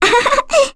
Lilia-Vox_Happy1.wav